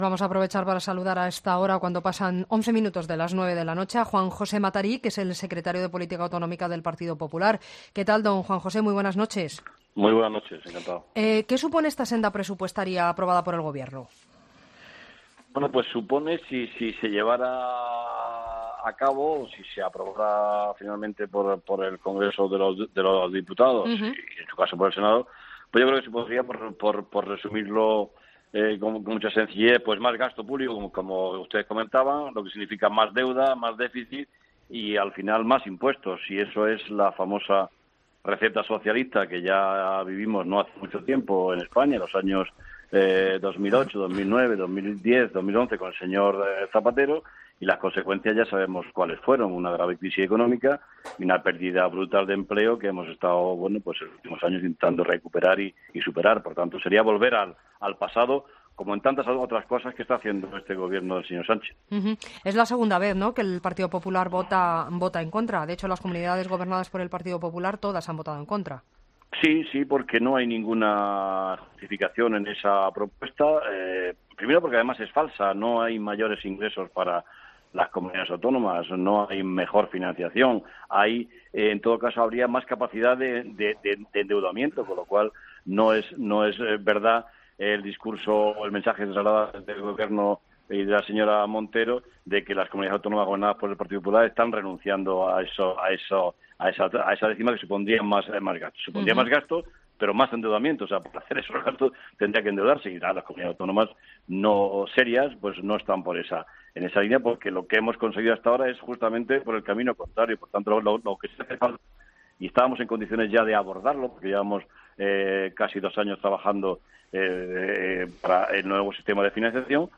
Entrevista a Juan José Matarí, secretario de política autonómica del Partido Popular